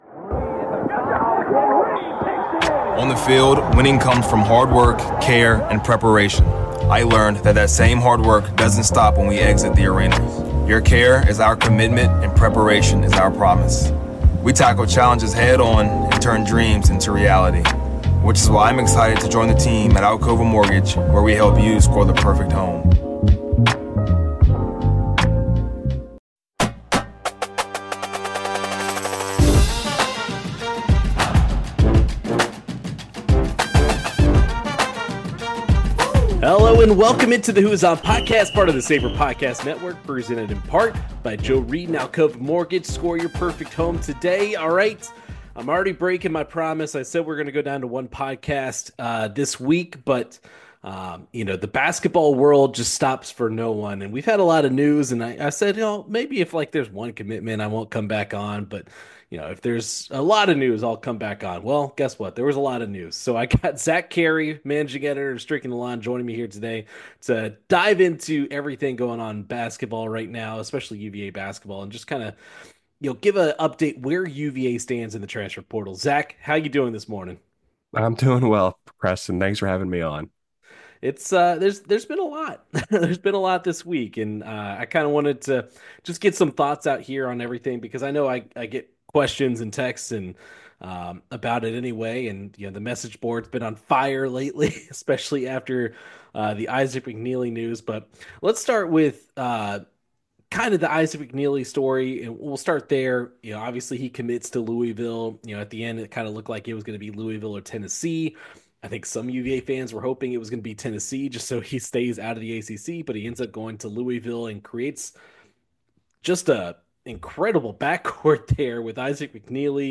An emergency podcast live from Disharoon Park!